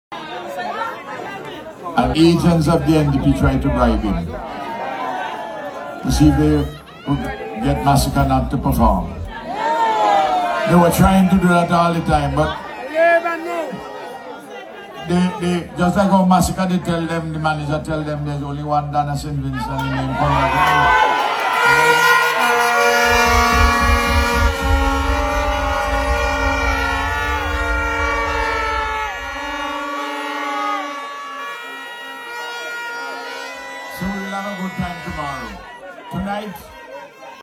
Speaking to a large crowd of supporters, Gonsalves claimed that Masicka’s management team informed the ULP that individuals linked to the opposition had approached them with an offer to redirect the artiste’s scheduled performance.
Audio recording of Ralph Gonsalves accusing NDP of buying out Dancehall Artiste “Masicka”